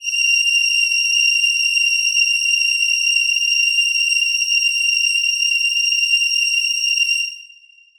Choir Piano